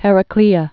(hĕrə-klēə)